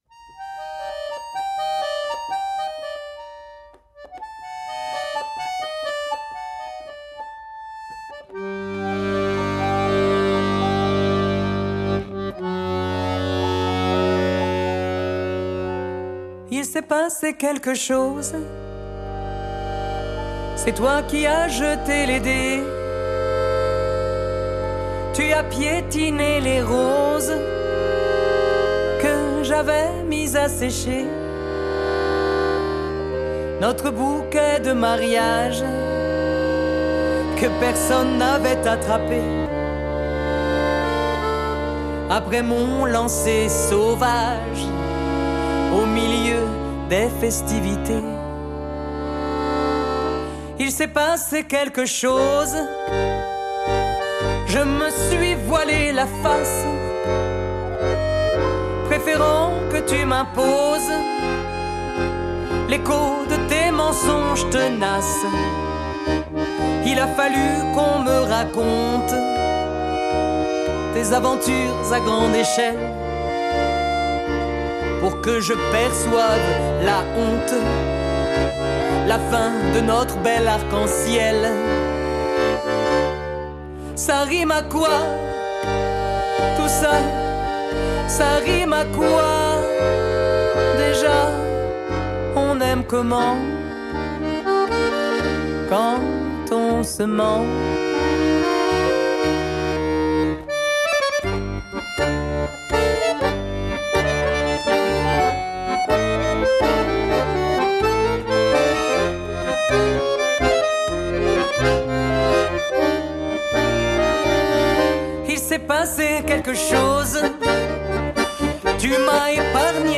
Accordéon
chœurs